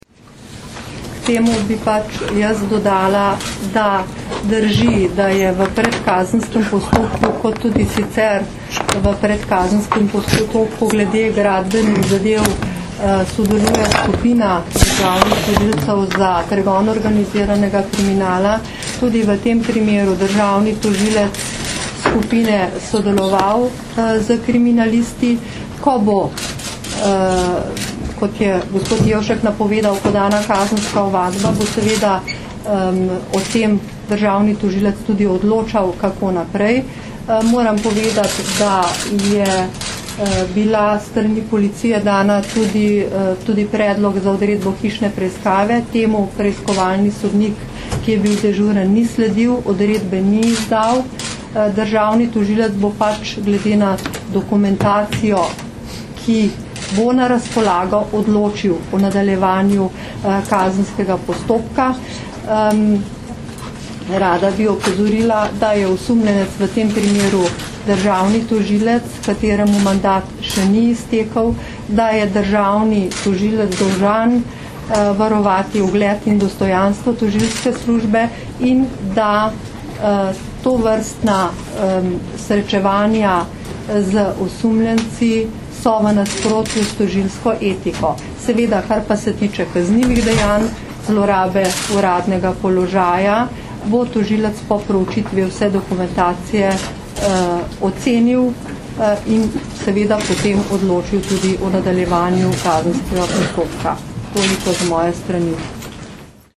Zvočni posnetek izjave Barbare Brezigar (mp3)